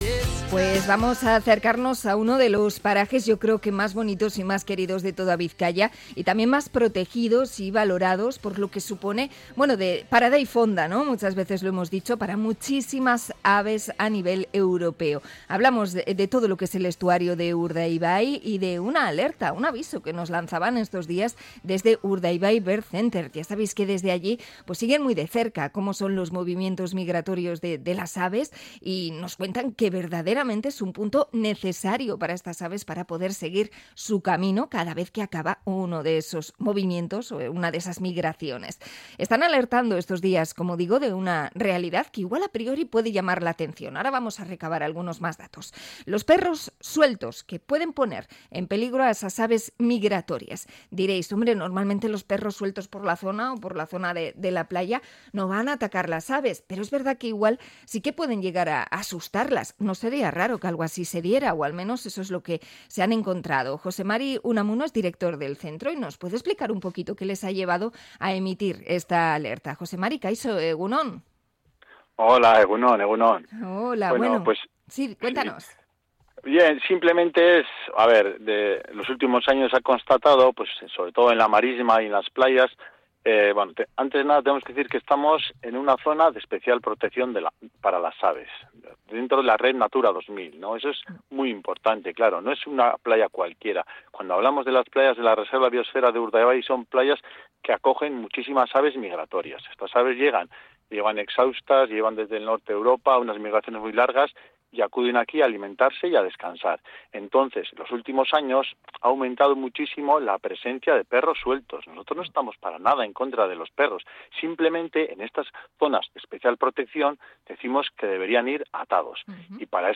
Entrevista a Urdaibai Bird Center por el peligro de los perros en Urdaibai